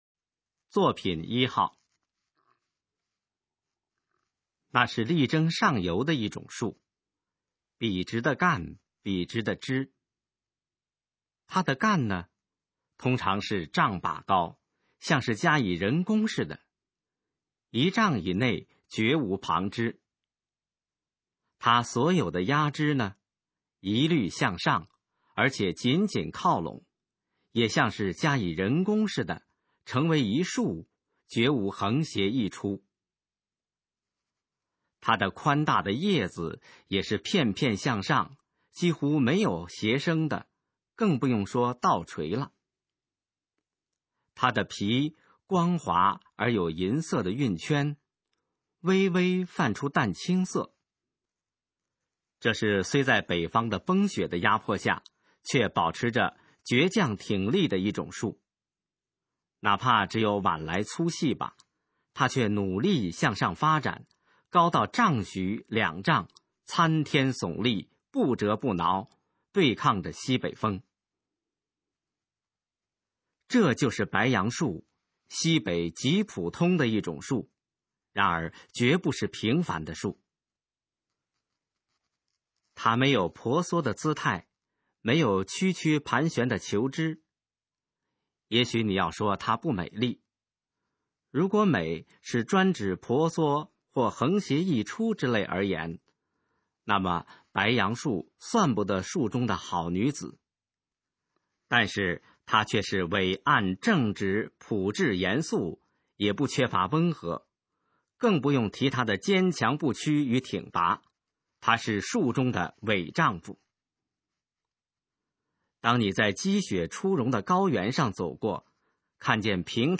首页 视听 学说普通话 作品朗读（新大纲）
《白杨礼赞》示范朗读_水平测试（等级考试）用60篇朗读作品范读　/ 佚名